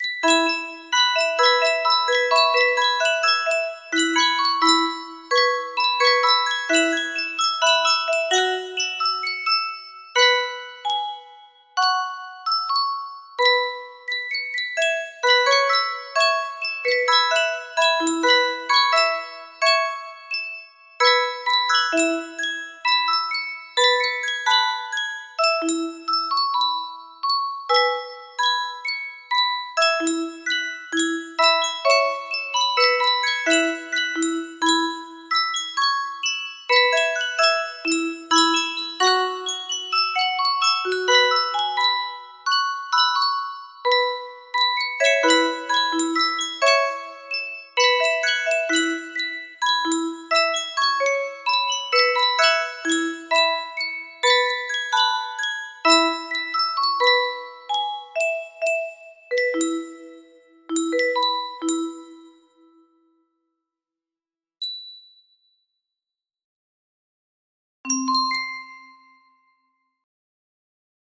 Full range 60
Traditional Japanese stringed musical instrument